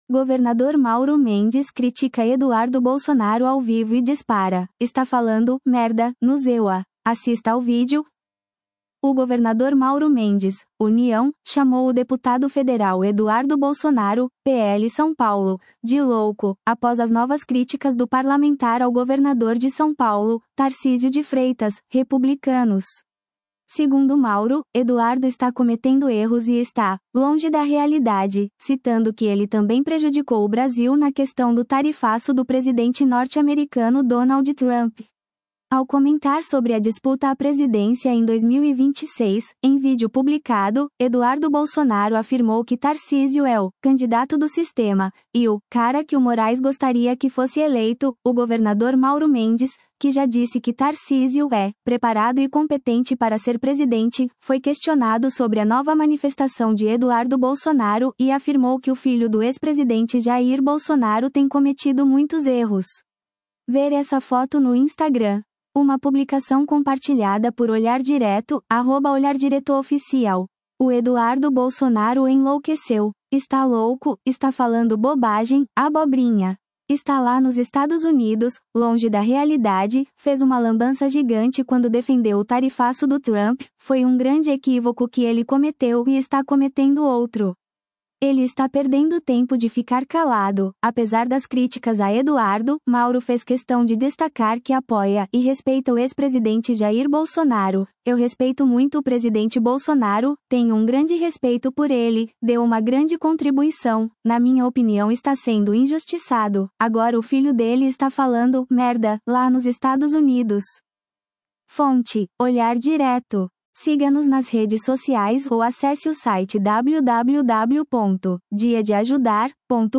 Governador Mauro Mendes critica Eduardo Bolsonaro ao vivo e dispara: Está falando 'merda' nos EUA; assista ao vídeo